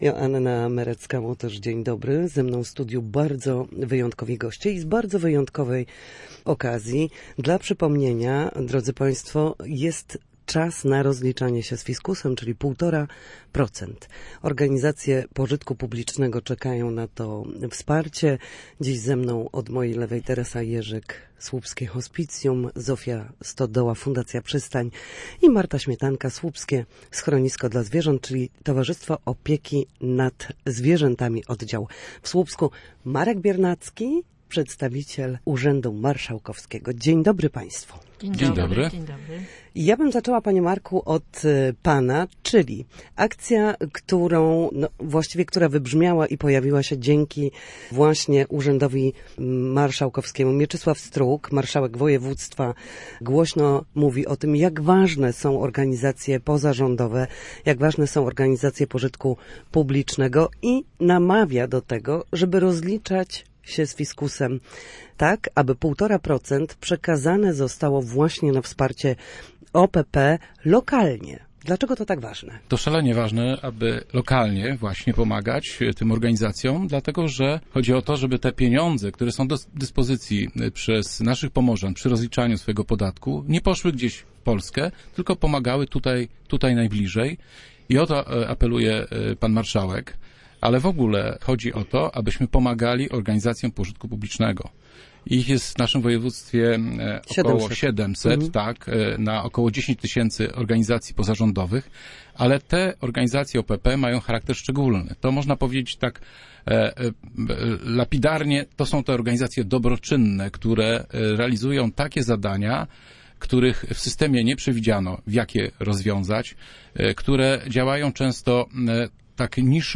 Na naszej antenie mówili o tym, jak ważne jest przekazanie 1,5 proc.